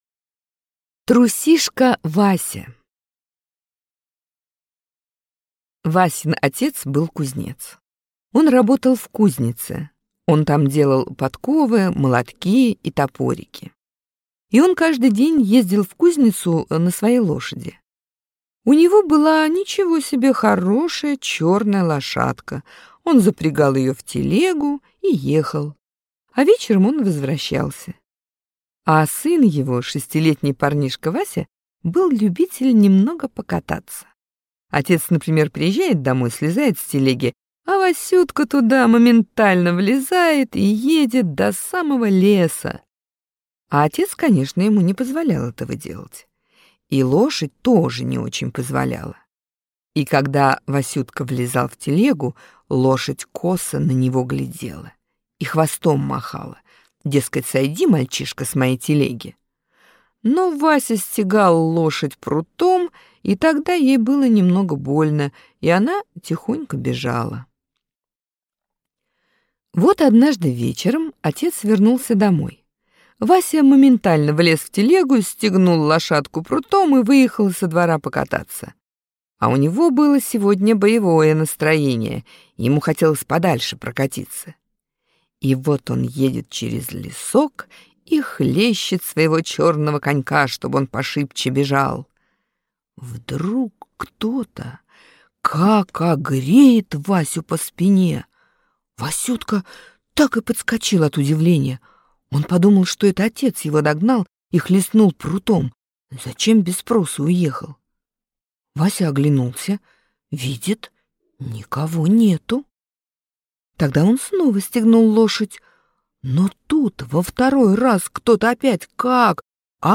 Аудиорассказ «Трусишка Вася»